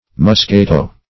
musketo - definition of musketo - synonyms, pronunciation, spelling from Free Dictionary Search Result for " musketo" : The Collaborative International Dictionary of English v.0.48: Musketo \Mus*ke"to\, n. See Mosquito .